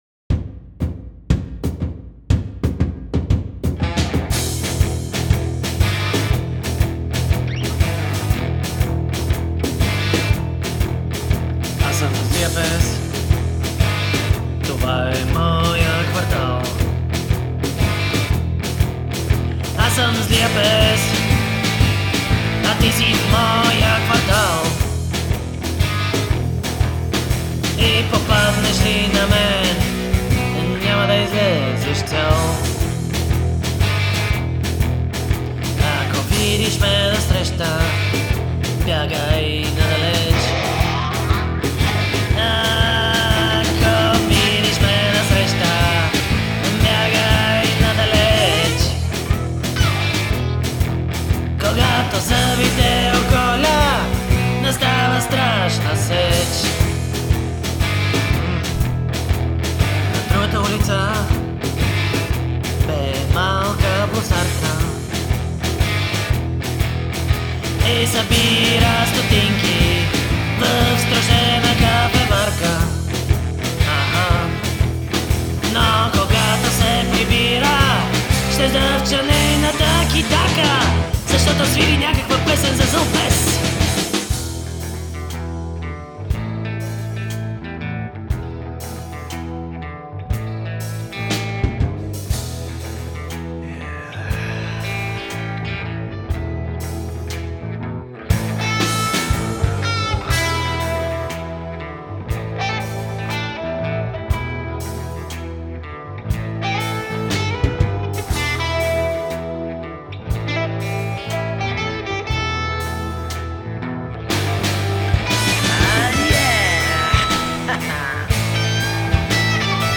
C’est une chanson blues